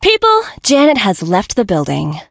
janet_die_vo_02.ogg